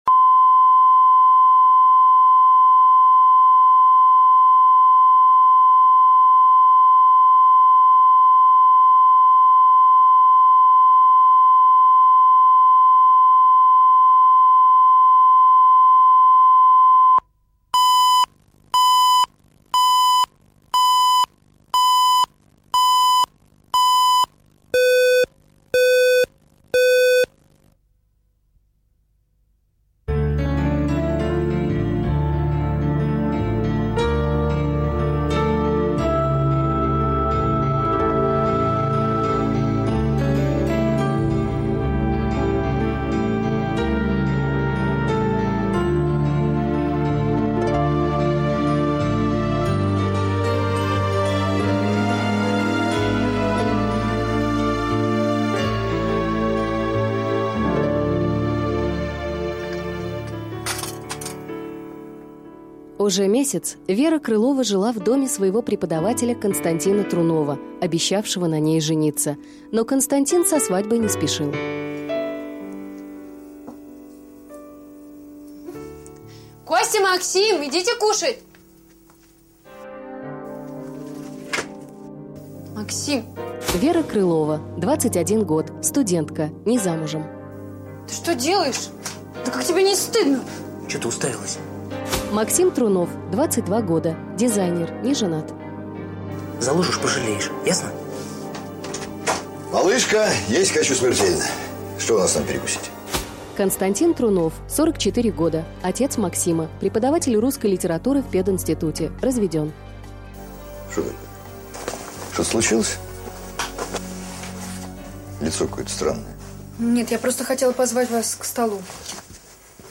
Аудиокнига Малышка